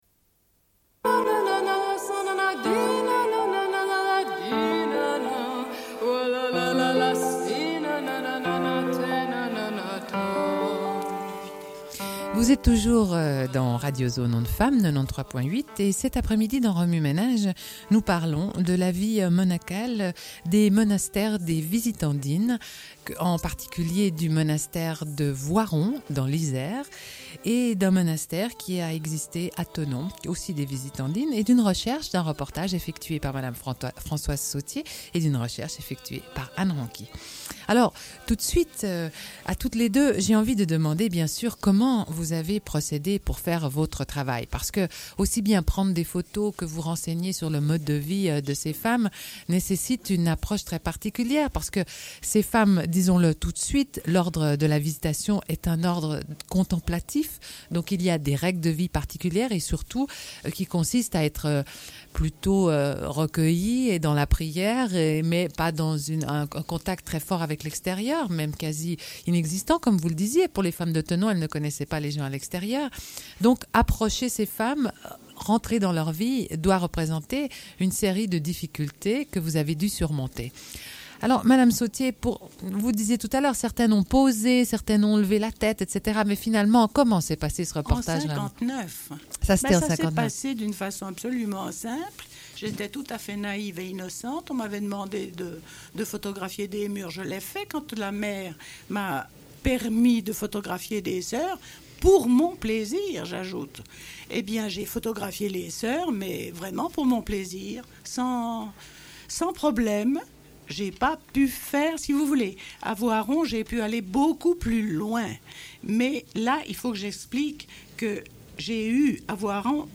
Une cassette audio, face B00:29:08
Radio Enregistrement sonore